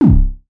REDD PERC (37).wav